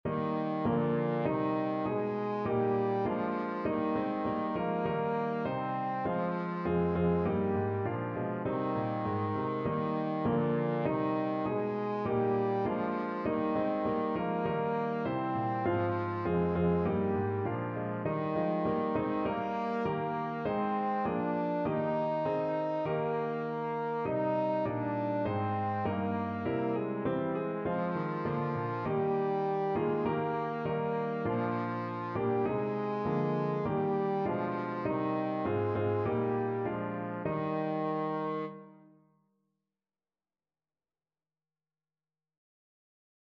Trombone
4/4 (View more 4/4 Music)
Eb major (Sounding Pitch) (View more Eb major Music for Trombone )
Moderato
Traditional (View more Traditional Trombone Music)